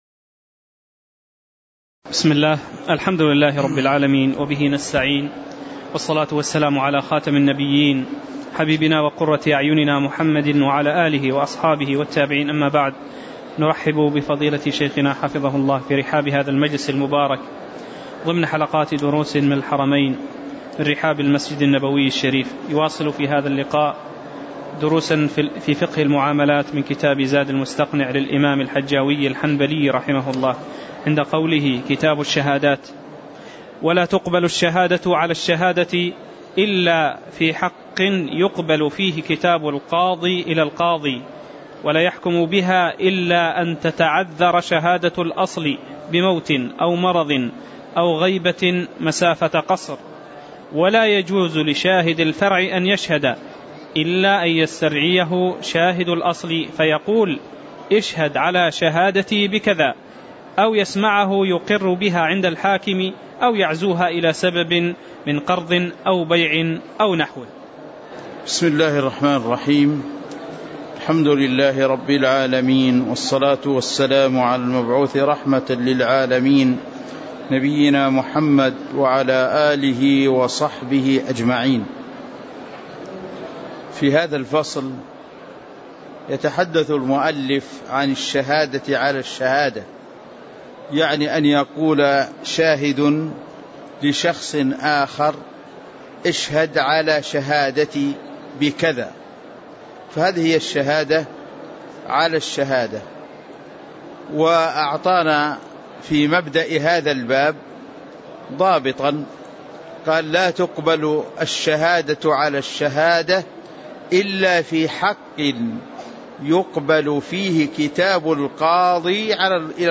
تاريخ النشر ٢١ رجب ١٤٣٨ هـ المكان: المسجد النبوي الشيخ